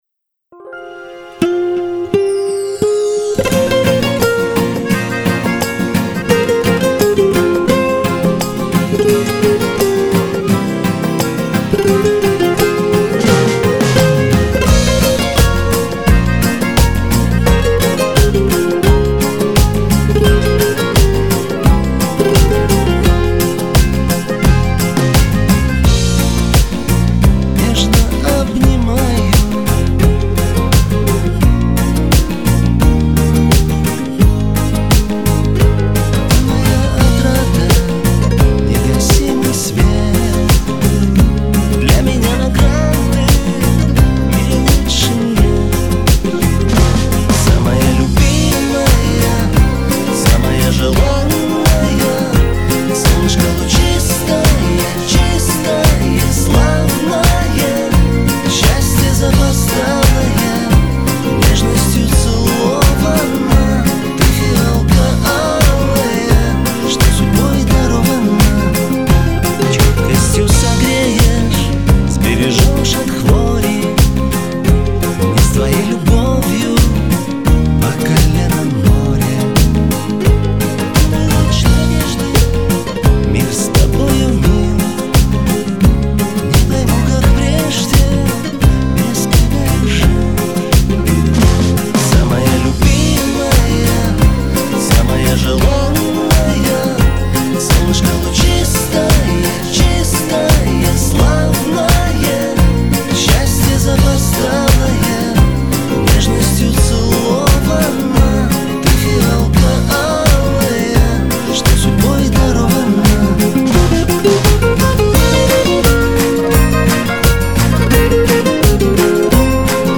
Модуляция